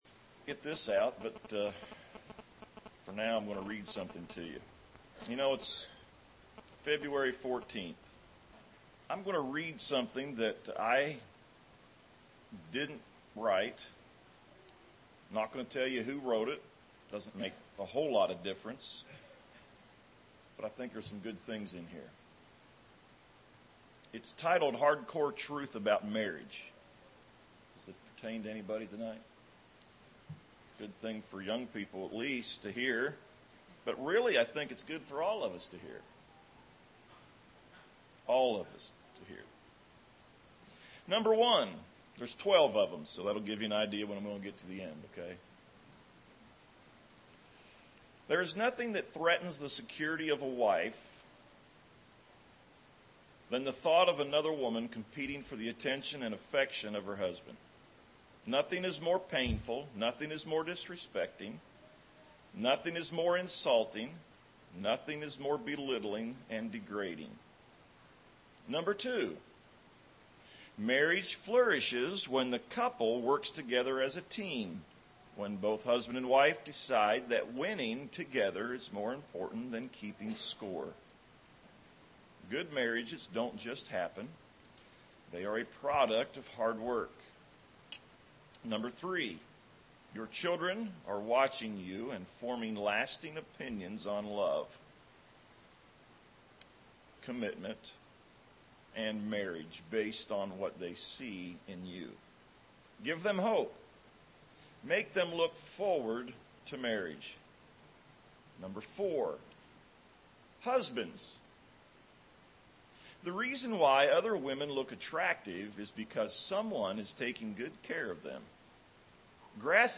An informal message